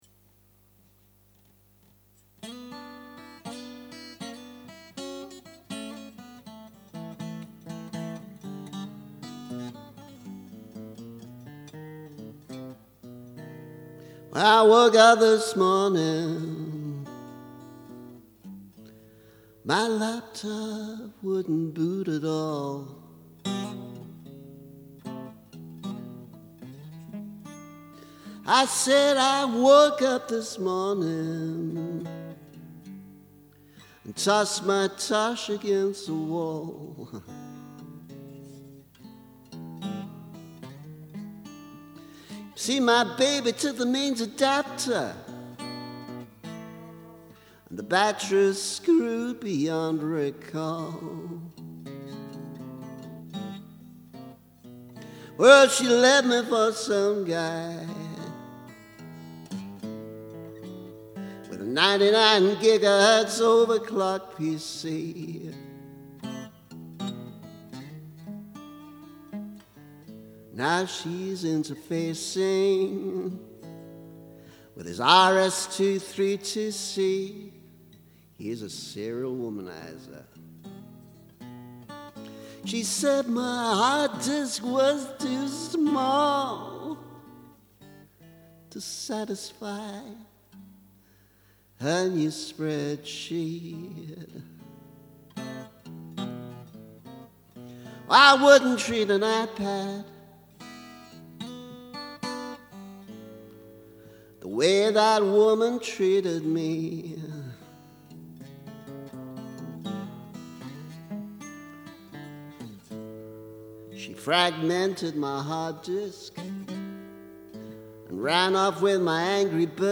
I wouldn’t have mentioned any of this if it weren’t for a ludicrous conversation in a pub with someone who apparently thought I was setting PC for Dummies to music rather than writing a mildly amusing blues parody.